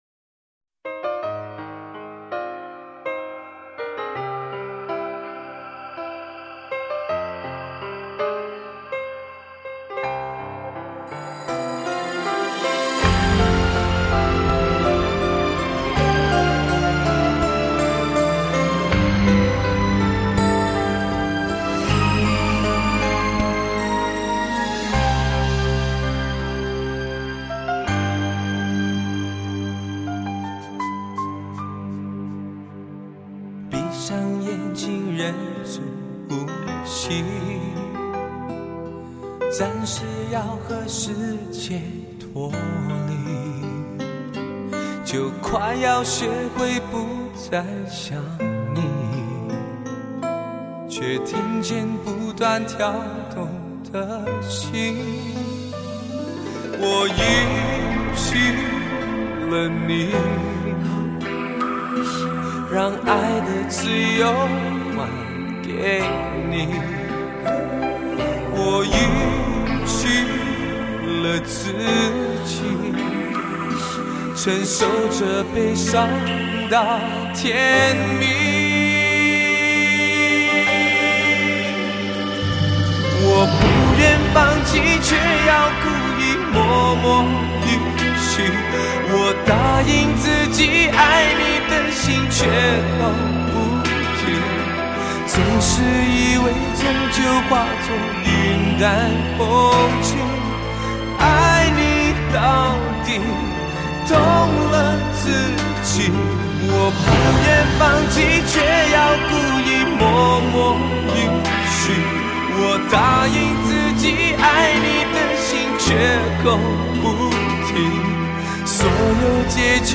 动人的歌声，全新的爱情宣言。